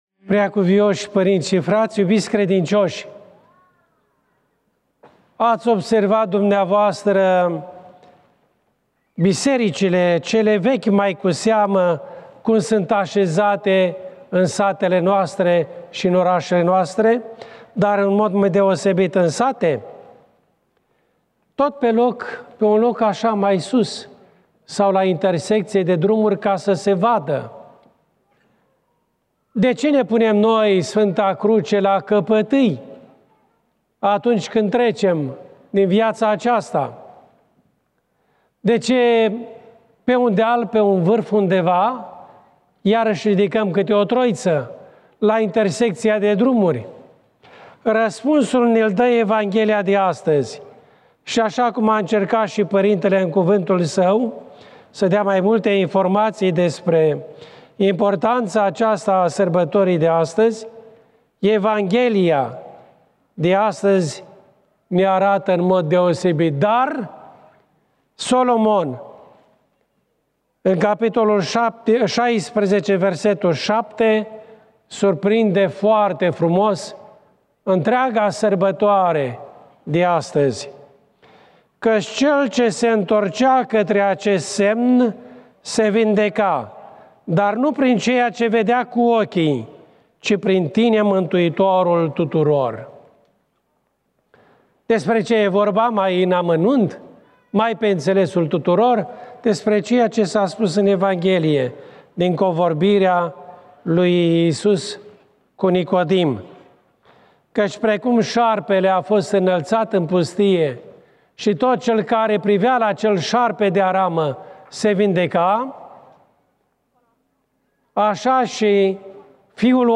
Predică IPS Bartolomeu Anania